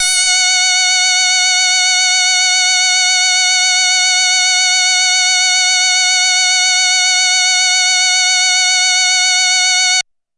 标签： F6 MIDI音符-90 罗兰-JX-3P 合成器 单票据 多重采样
声道立体声